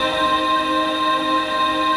ATMOPAD13.wav